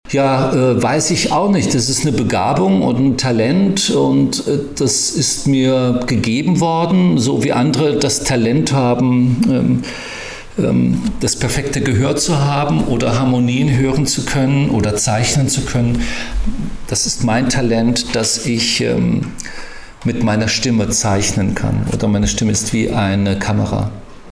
25 Jahre Harry Potter - Rufus Beck im Interview - PRIMATON